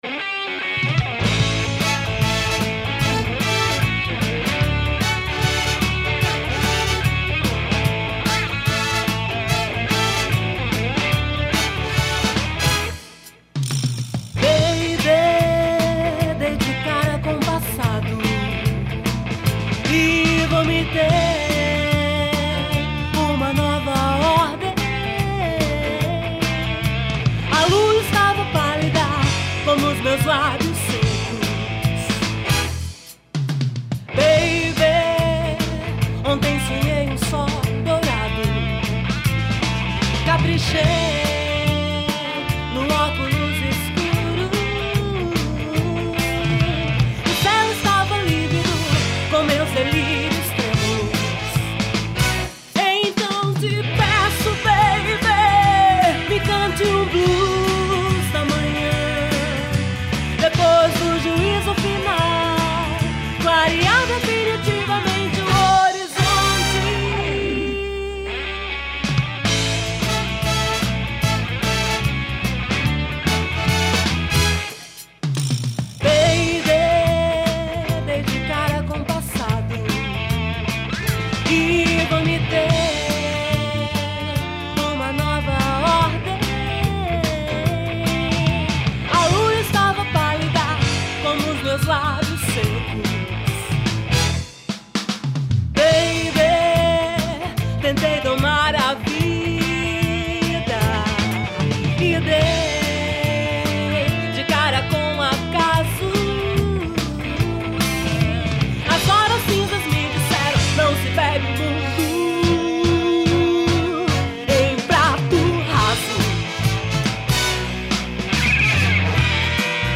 1705   03:34:00   Faixa:     Rock Nacional